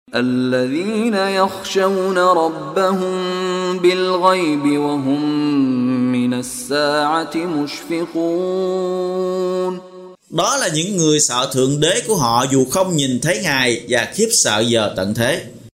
Đọc ý nghĩa nội dung chương Al-Ambiya bằng tiếng Việt có đính kèm giọng xướng đọc Qur’an